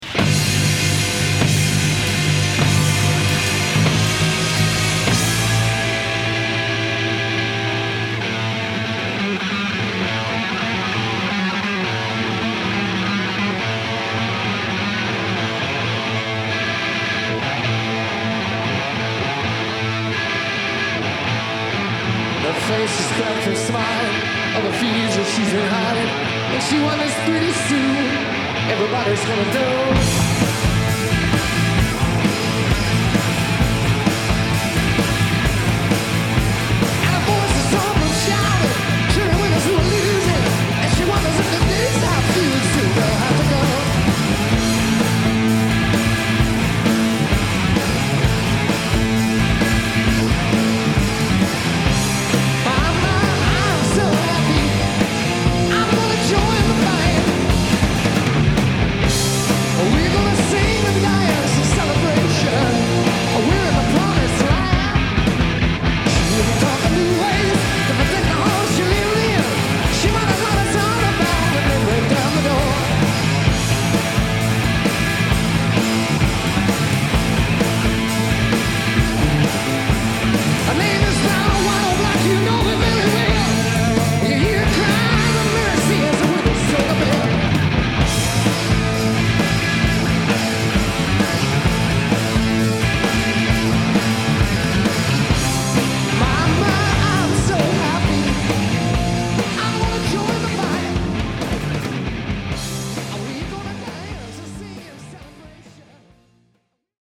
ミキサーの設定を変えてBASSをゼロにしてもう一度。ZO-3はチューニングが合わない。